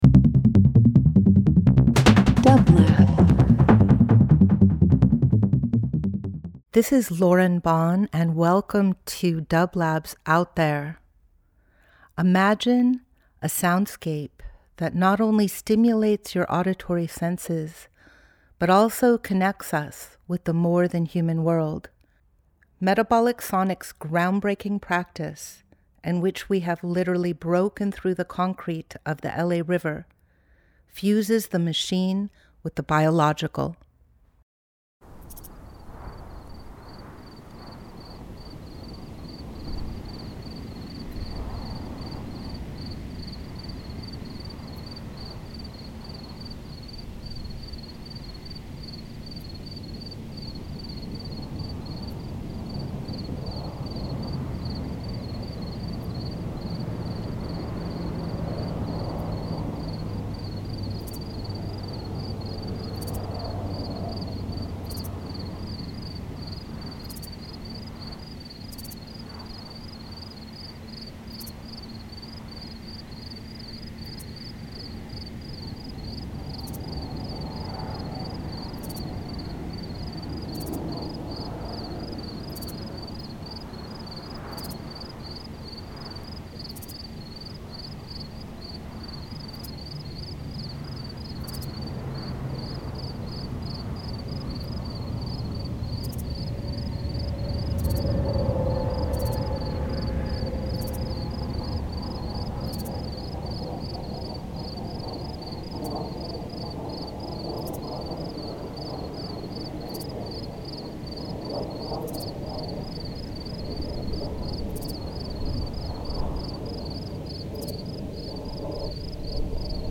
Each week we present field recordings that will transport you through the power of sound.
Let’s listen to the sounds of our pollinator friends! We will hear crickets in the Payahuunadü desert, algorithmic sounds of ant movements, a bee hive at the Metabolic Studio and Bark beetles in a cornfield.